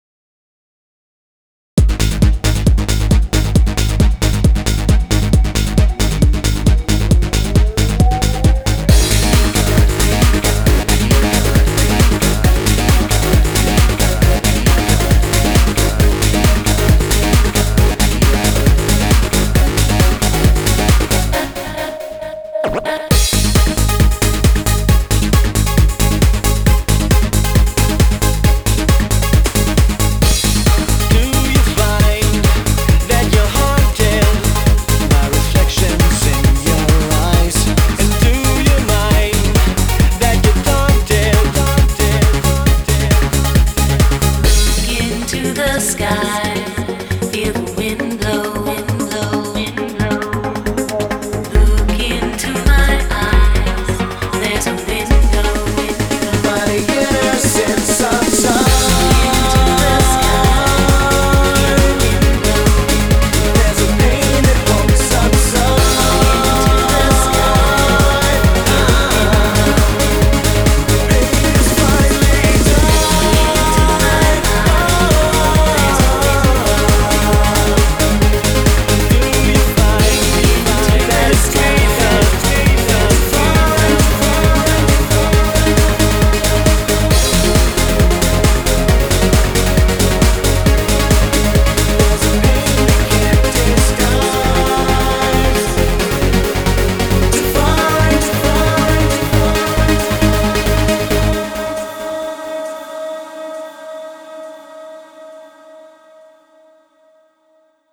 BPM135